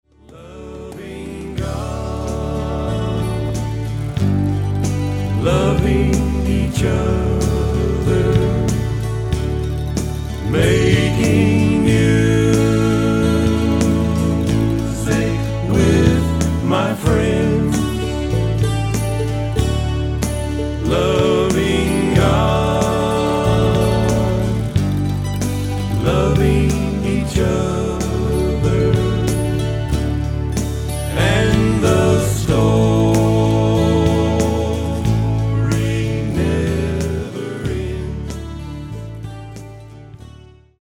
Autoharp, Lead & Harmony Vocals
Fiddle